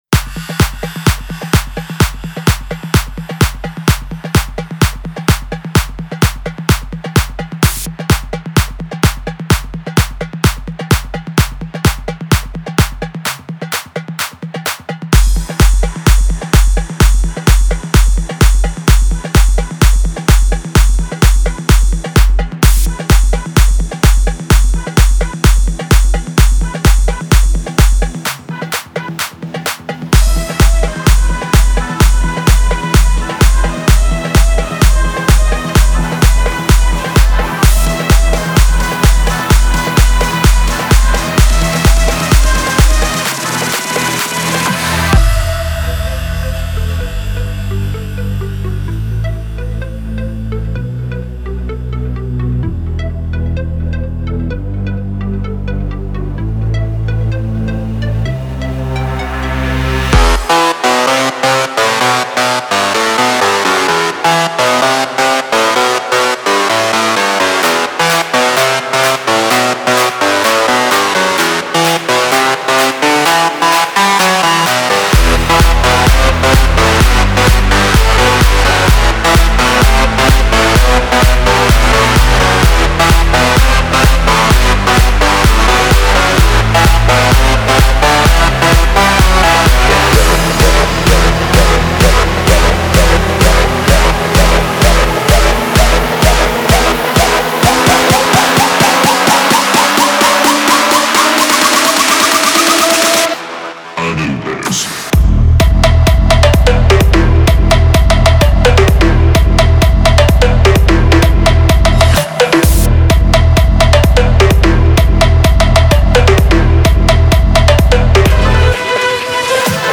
Категория: Электро музыка » Электро-хаус